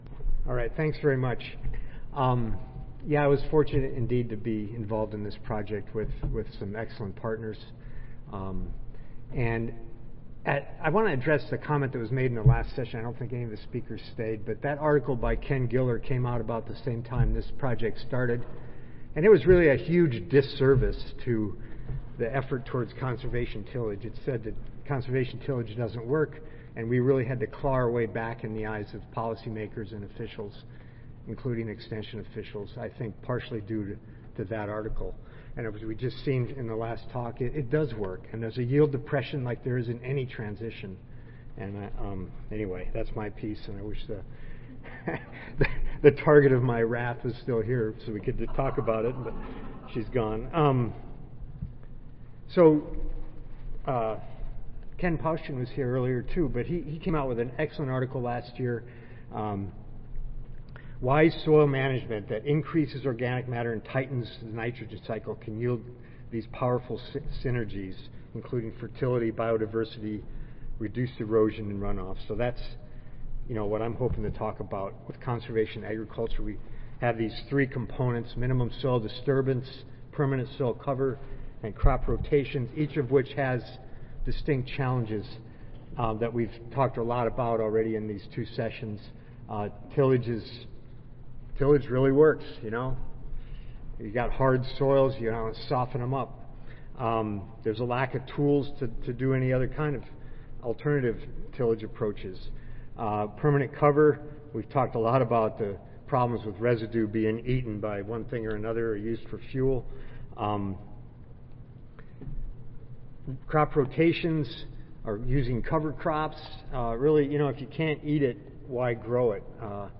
University of Wyoming Audio File Recorded Presentation